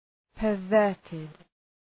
Προφορά
{pər’vɜ:rtıd}